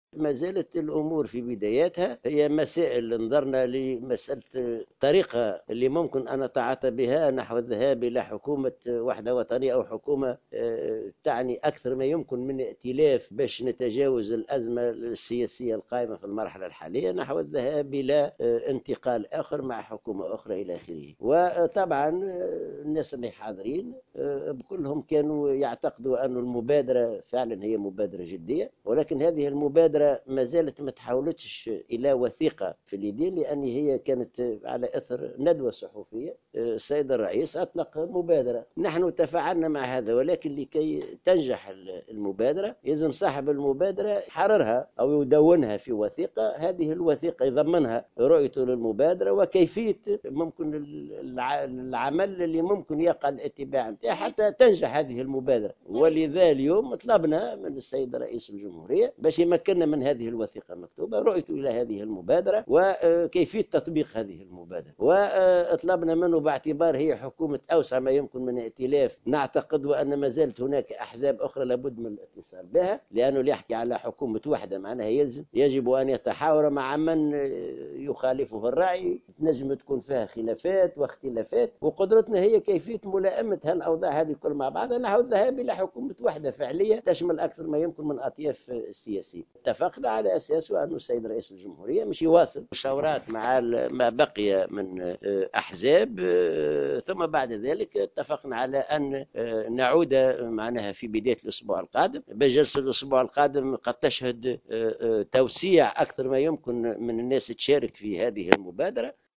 أكد الأمين العام لإتحاد الشغل حسين العباسي مساء اليوم الأربعاء في تصريح لمراسلة الجوهرة "اف ام" أن مبادرة رئيس الجمهورية بخصوص تشكيل حكومة وحدة وطنية يجب أن تدون وتحرر حتى يتم اتباع الخطوات الواردة فيها من أجل تجسيمها وإنجاحها على حد قوله.